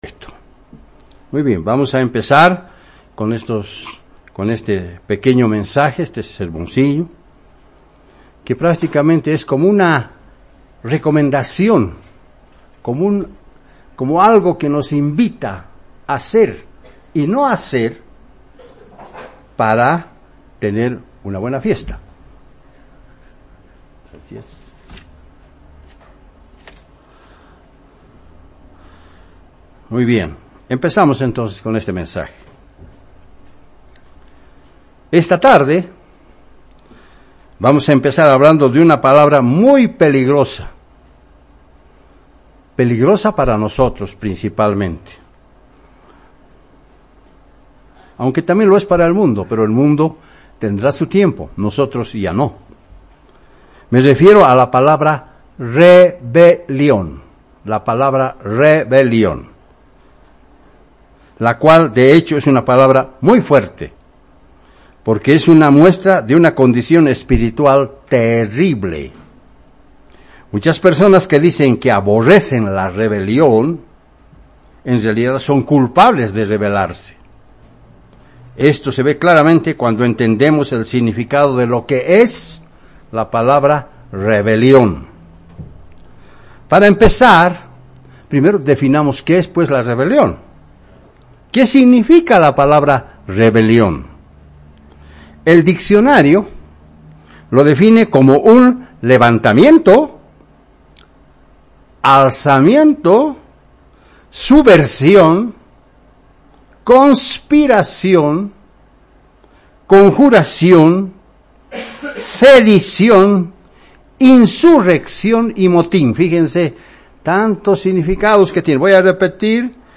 Sermones
Given in La Paz